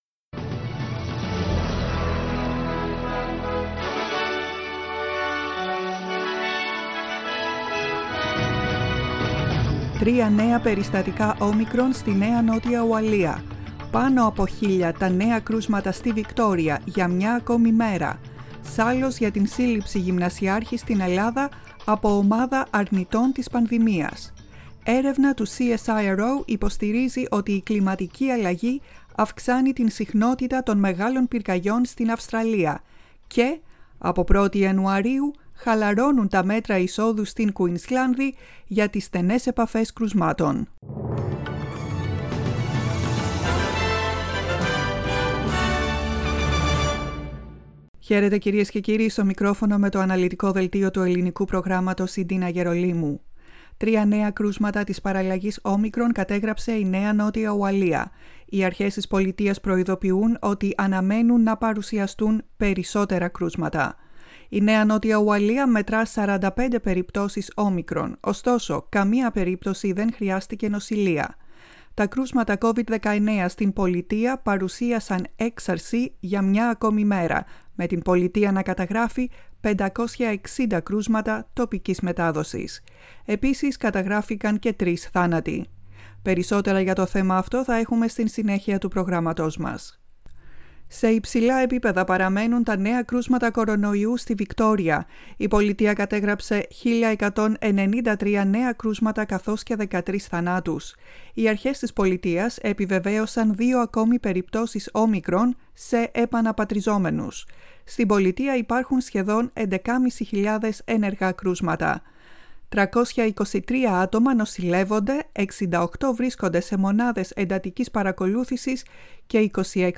Το κεντρικό δελτίο ειδήσεων του Ελληνικού Προγράμματος.